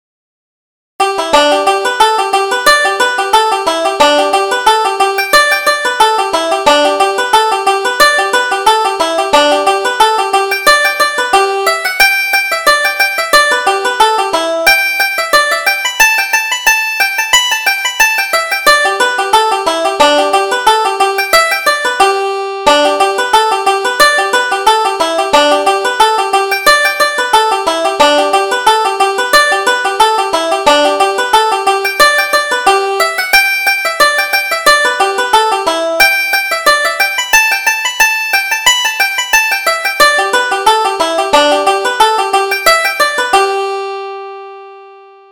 Reel: More Power to Your Elbow